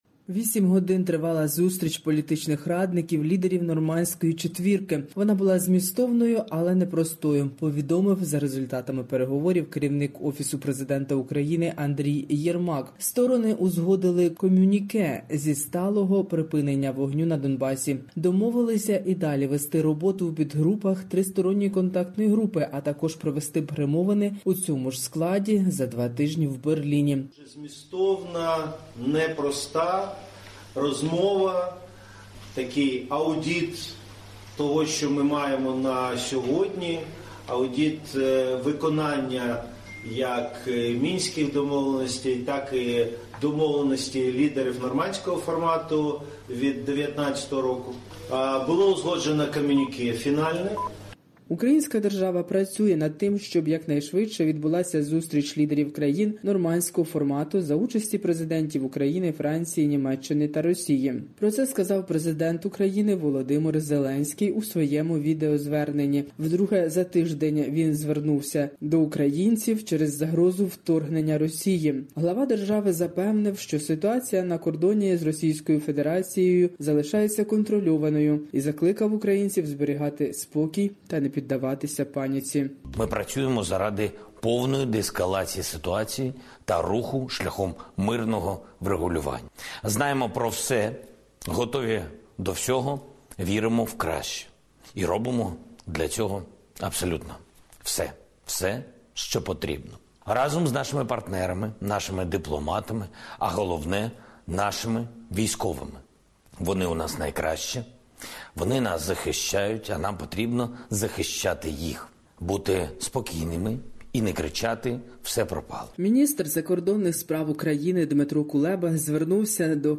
Євробачення-2022 та більше для радіослухачів SBS Ukrainian від київської журналістки сьогодні...